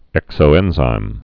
(ĕksō-ĕnzīm)